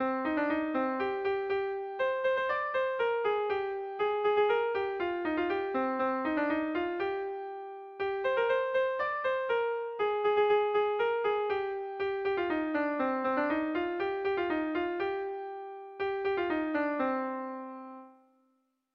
Dantzakoa
ABDE...